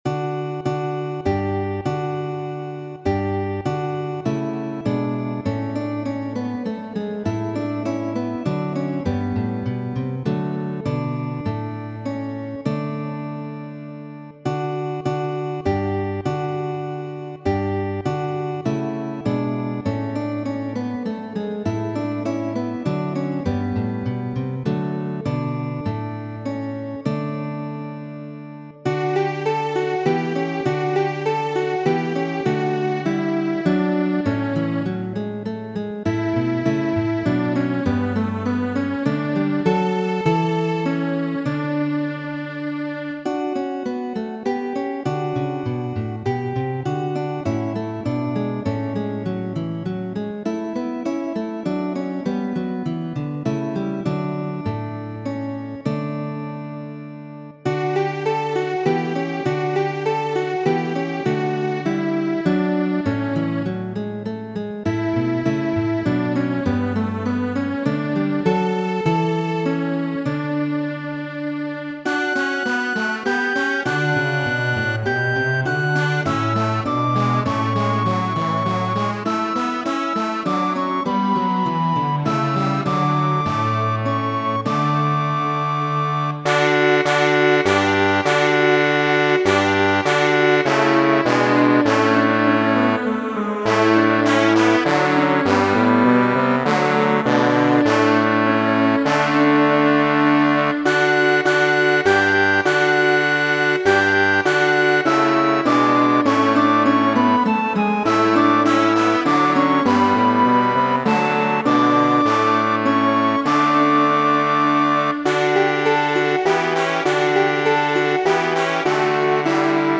Pavanon 6-an, preskaŭ Sarabandon, kiu estis danco malpermesita tiutempe.
Muziko: Pavano, preskaŭ Sarabando, de Ludoviko Milan', muzikisto valensja de de la 16-a jarcento.